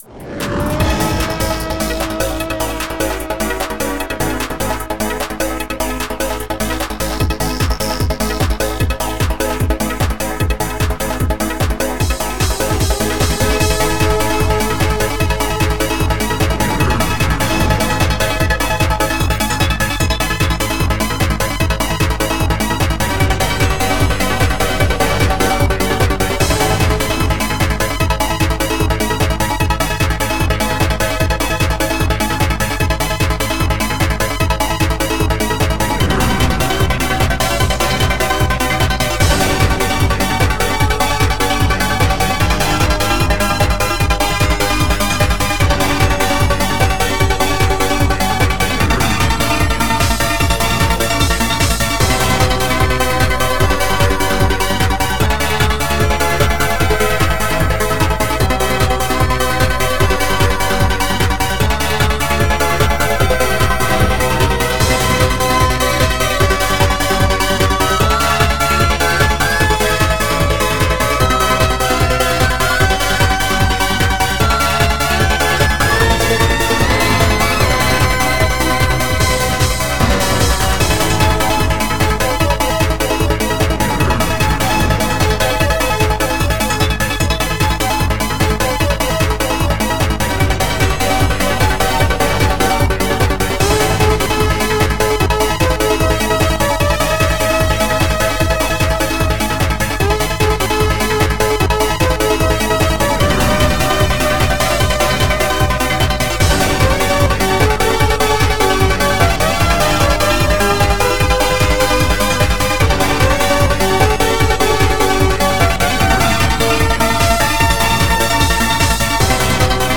xm (FastTracker 2 v1.04)